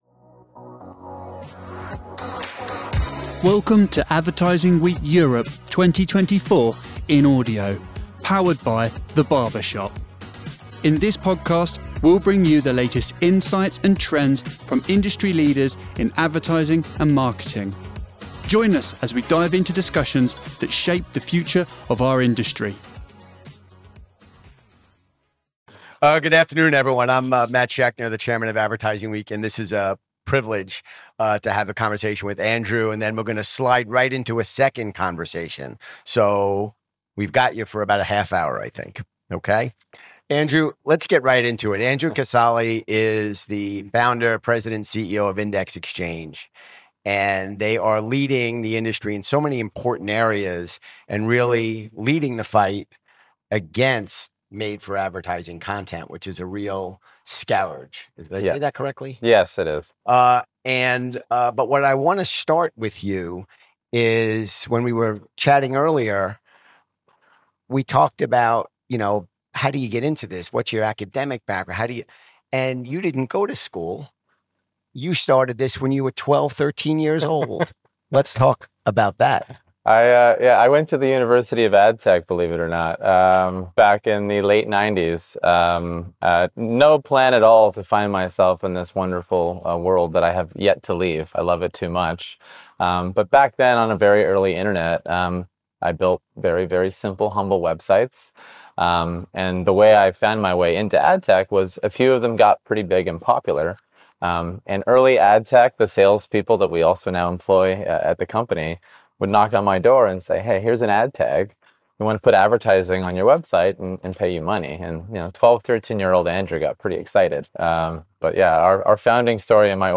Learn how to navigate the complexities of media quality in this insightful session.